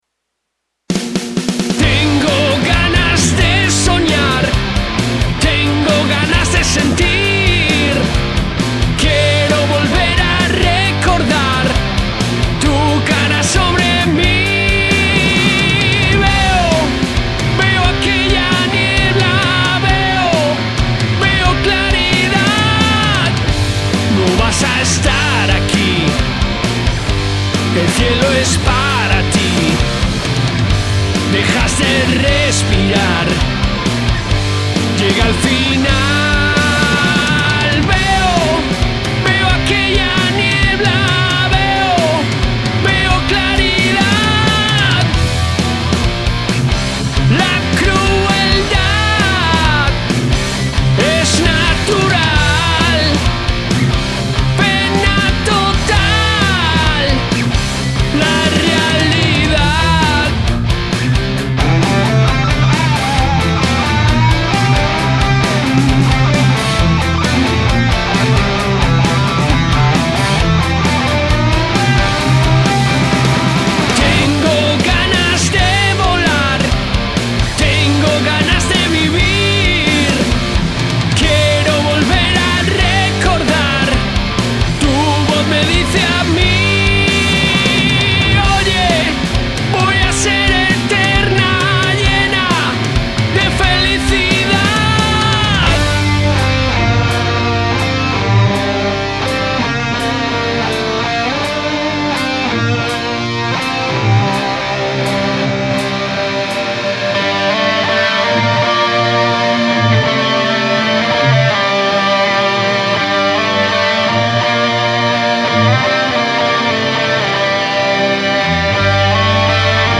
Rock en Español